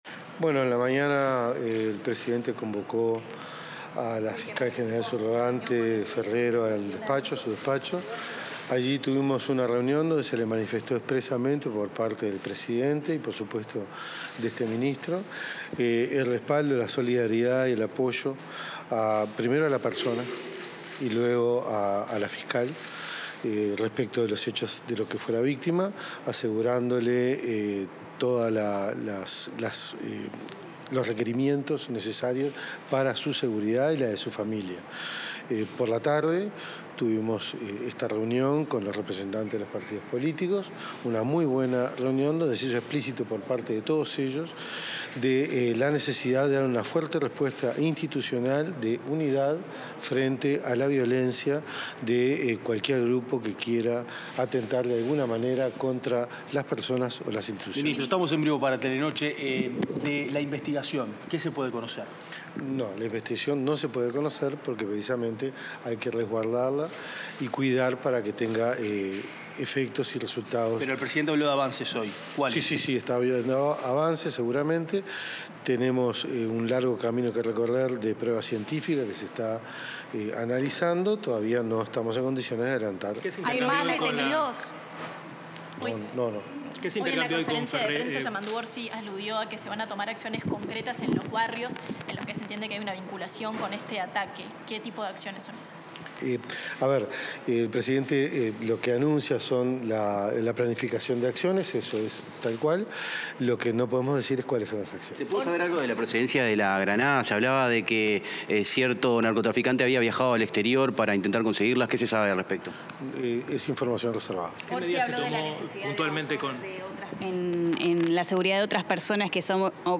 Declaraciones del ministro del Interior, Carlos Negro
Declaraciones del ministro del Interior, Carlos Negro 29/09/2025 Compartir Facebook X Copiar enlace WhatsApp LinkedIn Tras participar en una reunión con el presidente de la República, Yamandú Orsi, y referentes de los partidos políticos para dialogar sobre el atentado a la fiscal de Corte, el ministro del Interior, Carlos Negro, respondió a los medios informativos.